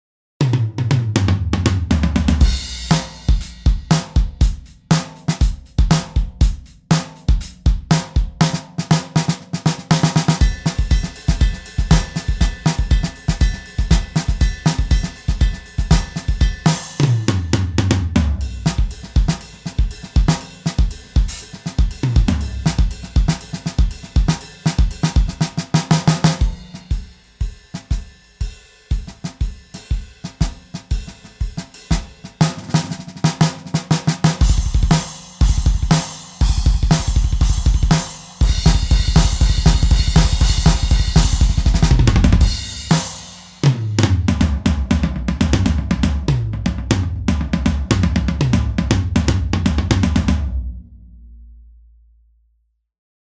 Instrument virtuel pour Kontakt (5 ou supérieur) ou Decent Sampler. La batterie unique de drumiBus échantillonnée en détails : 715 « samples », jusqu’à 9 couches de vélocités avec 10 « samples » différents chacune !!!
drumiBus DRUM - instrument DEMO.mp3